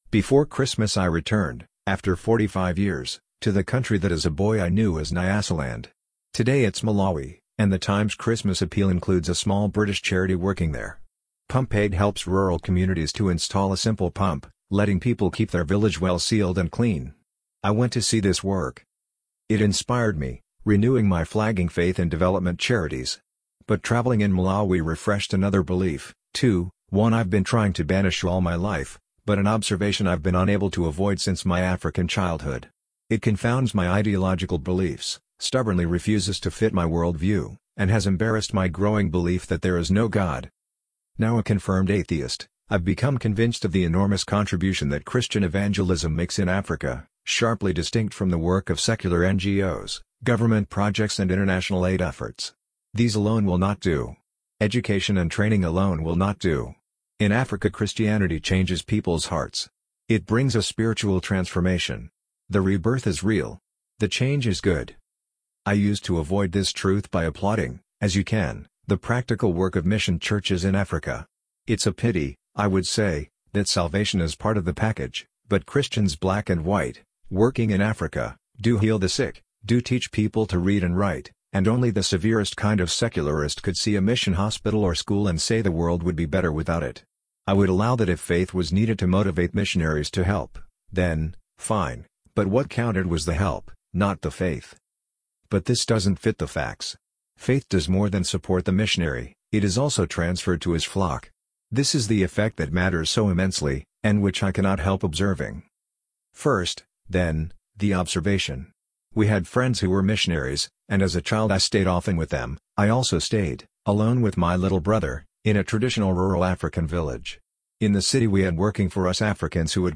Audio Version of this article
Thanks to Amazon Polly and audacity